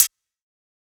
ClosedHH Quasi.wav